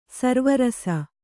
♪ sarva rasa